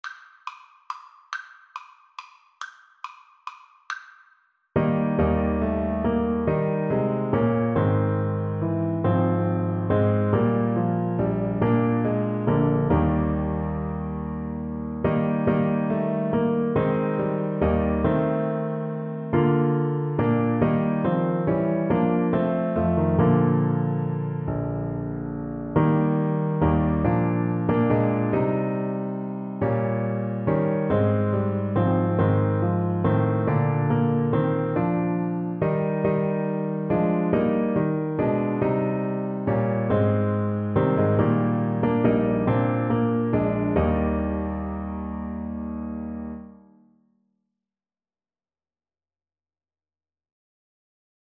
3/4 (View more 3/4 Music)
Classical (View more Classical Trombone Music)